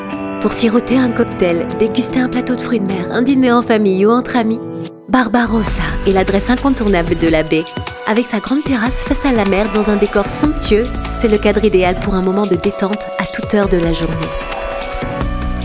Une voix, plusieurs styles
Une voix douce, posée, rassurante, souriante, dynamique, punchy
Je dispose donc d’un studio de post-production à l’acoustique étudiée et parfaitement insonorisé.
Restaurant-1.mp3